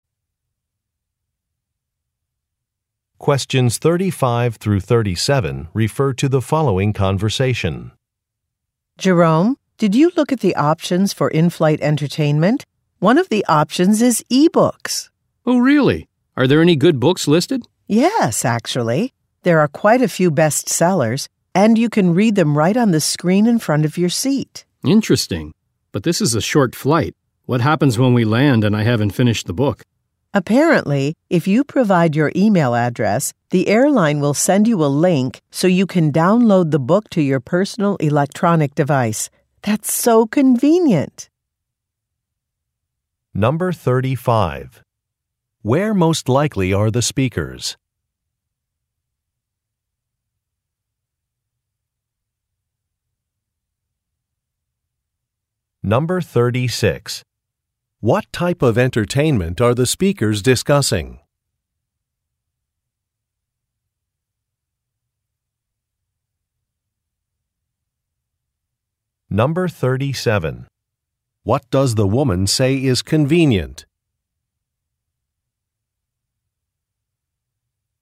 Question 35 - 37 refer to following conversation: